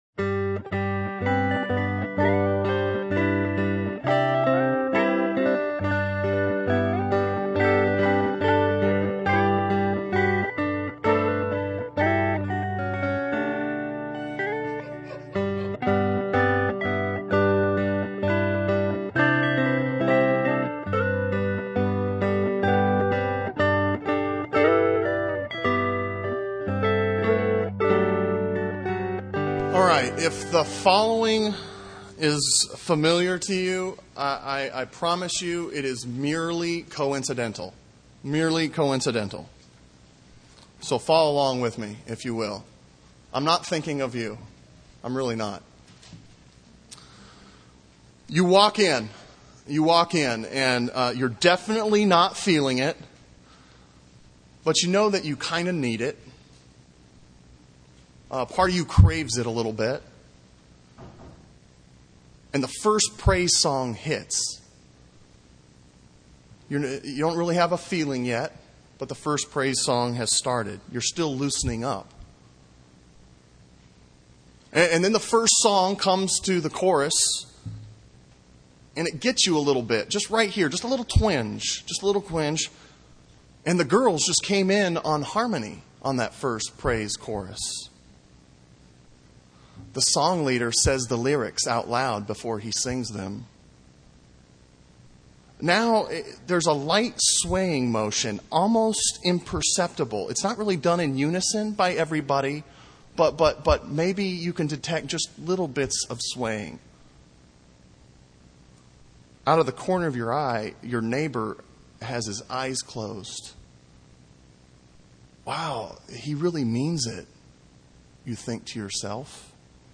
Sermon on Hosea 9:1-17 from August 3, 2008